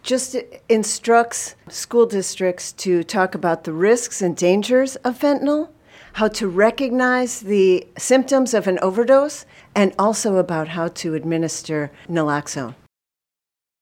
That is the bill’s sponsor, state Senator Laura Ellman.